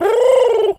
pigeon_call_angry_05.wav